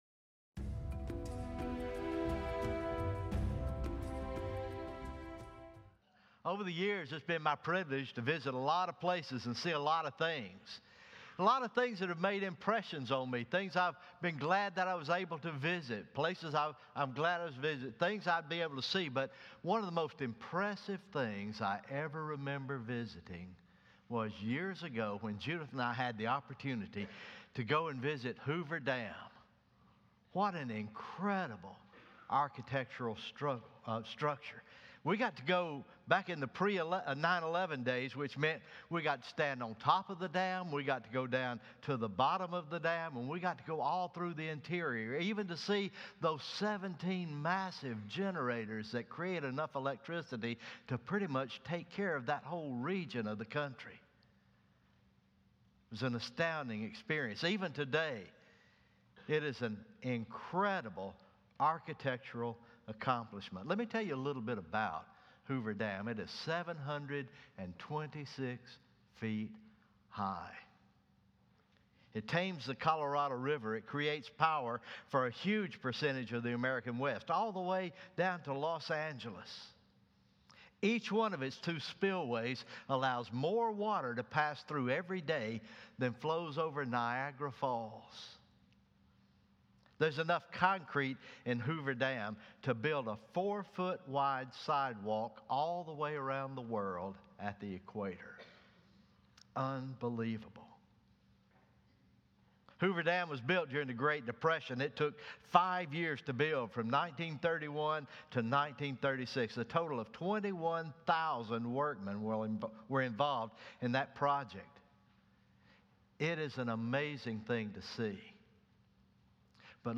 Morning Worship